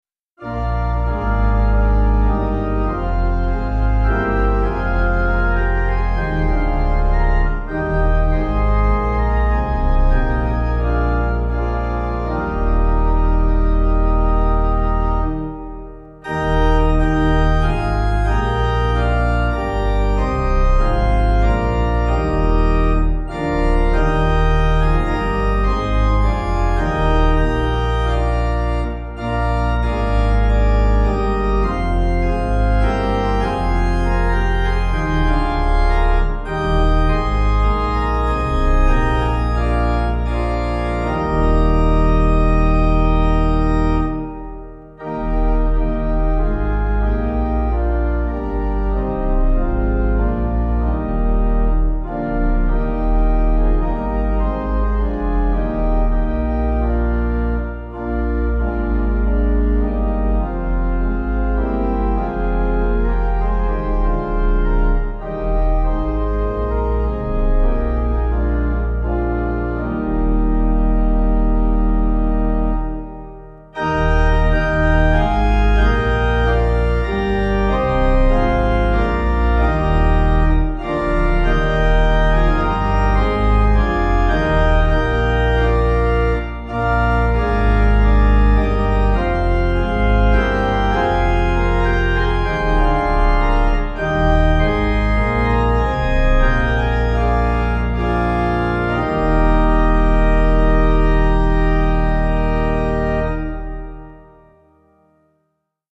Organ
(CM)   3/Eb